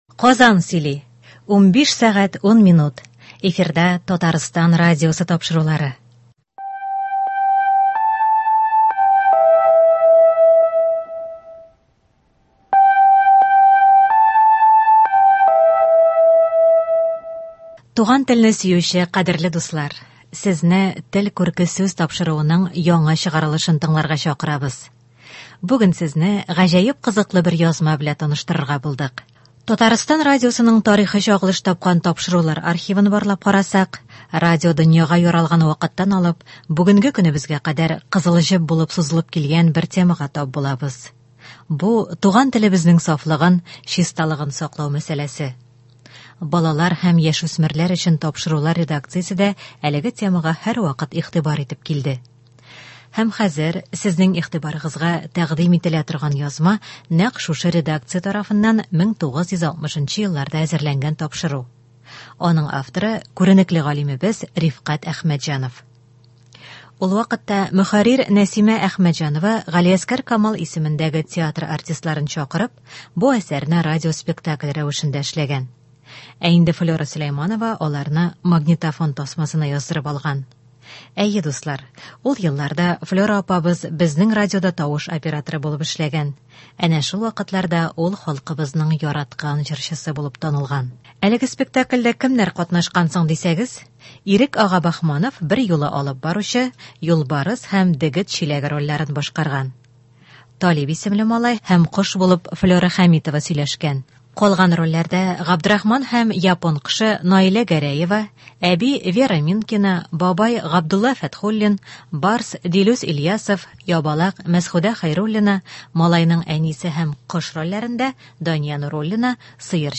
Бу тапшыруда күренекле галимнәр, язучылар халкыбызны дөрес сөйләшү, дөрес язу серләренә өйрәтә.